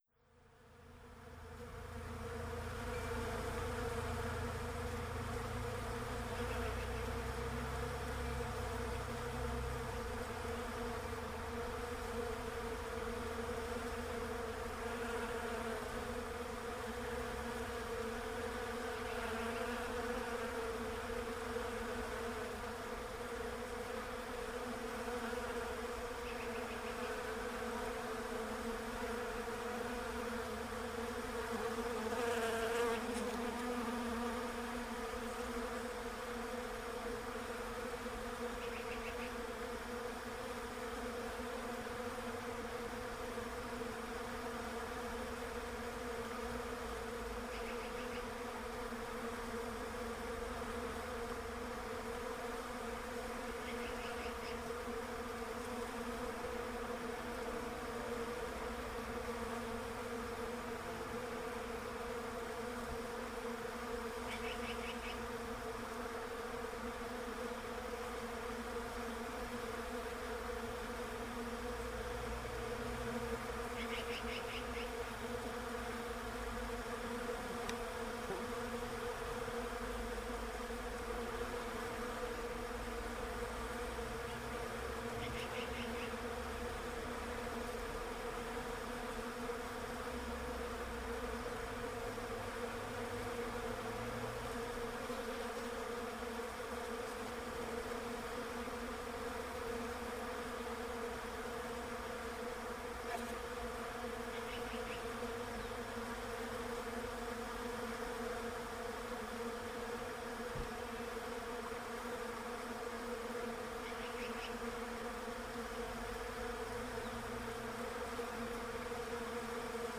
bees